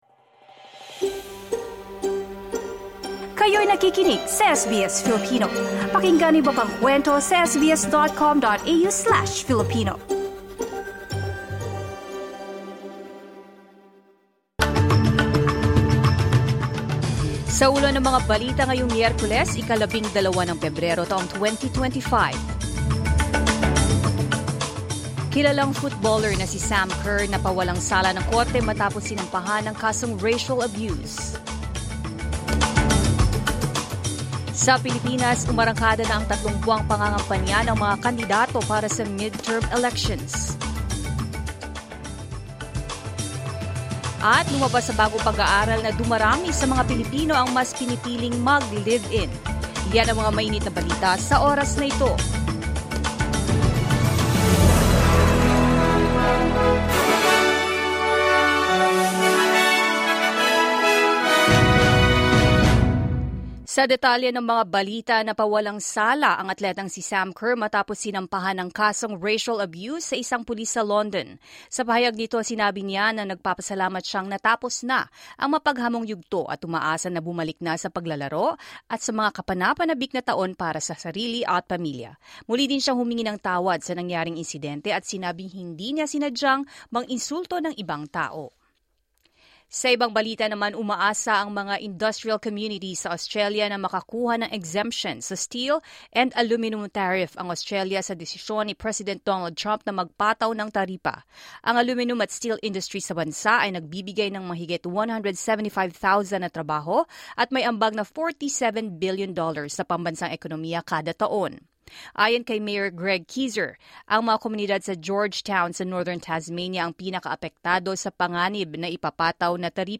SBS News in Filipino, Wednesday 12 February 2025